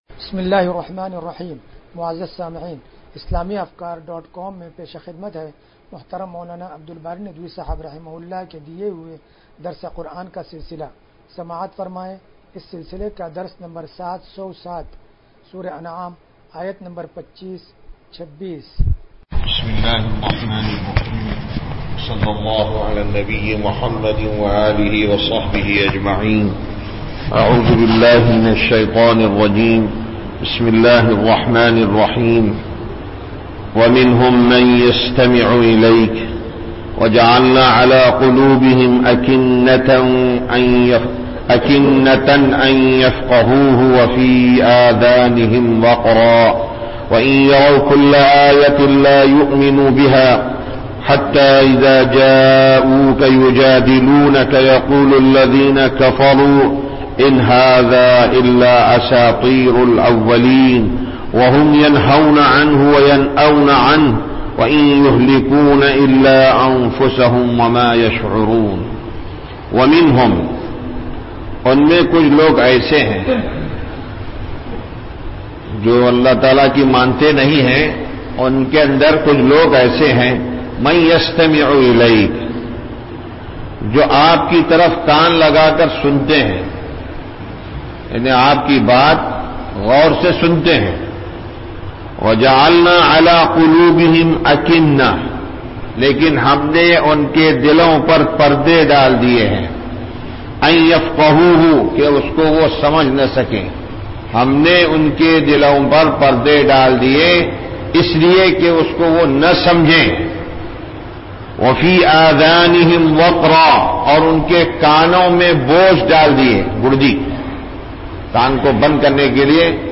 درس قرآن نمبر 0707